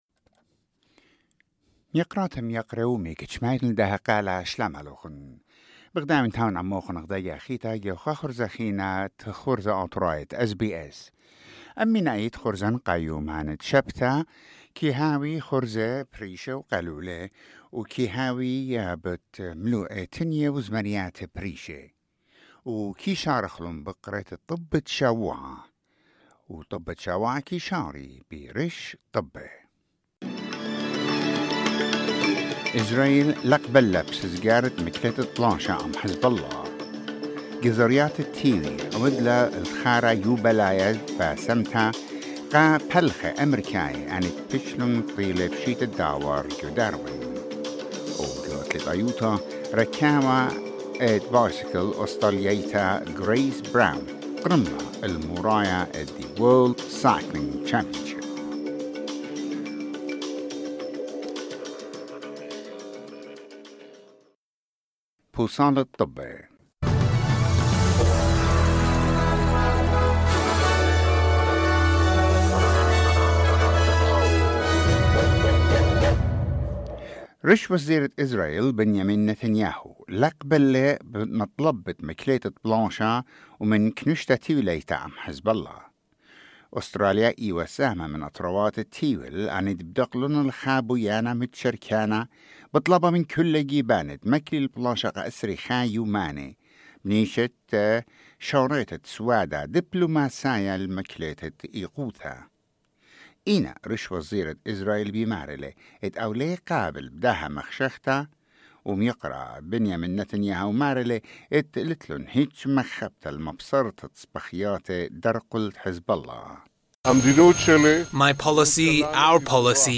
SBS Assyrian: Weekly news wrap